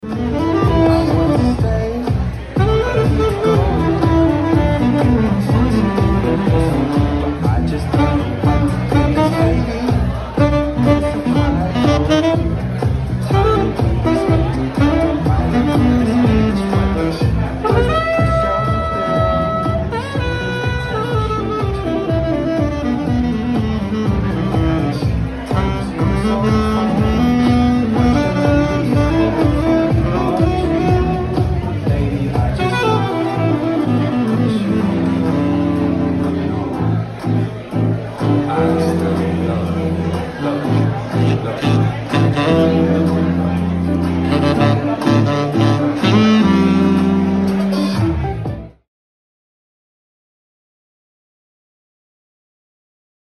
Sax
Freestyle Sax